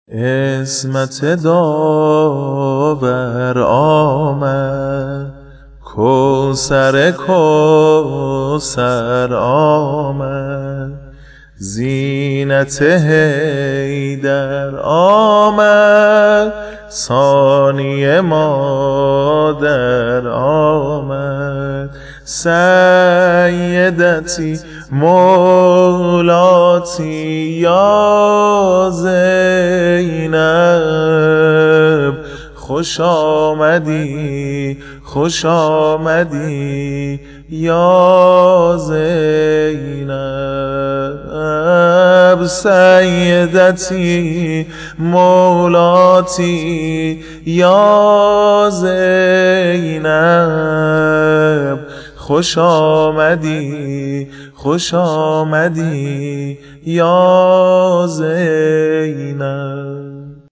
زینب کبری - - -- - -زمزمه میلاد- - -